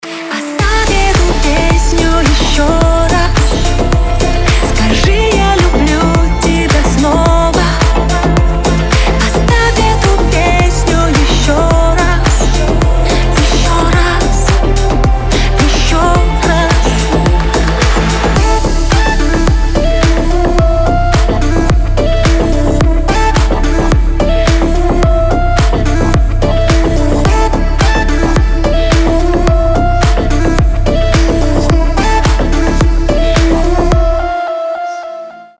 • Качество: 320, Stereo
поп
dance